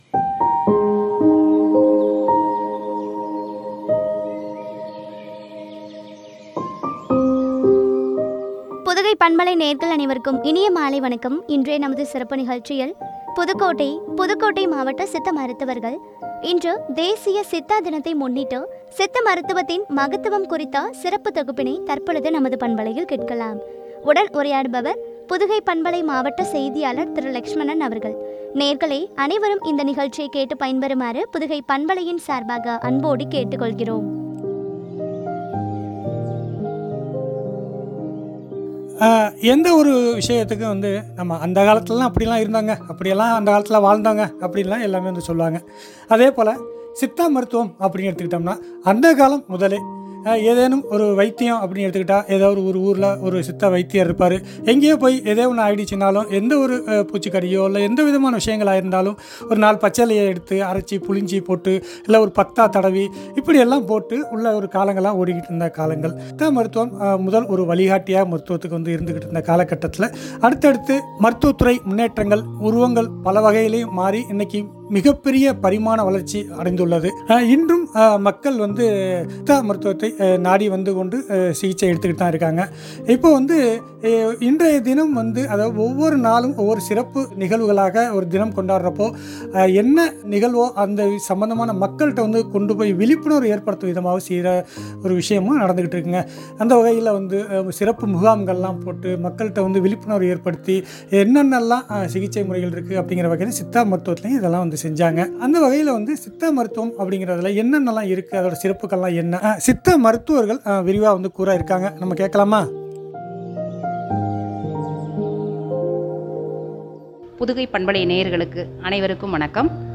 புதுக்கோட்டை மாவட்ட சித்த மருத்துவர்கள் ஆற்றிய சித்த மருத்துவத்தின் மகத்துவம் பற்றிய சிறப்பு தொகுப்பு.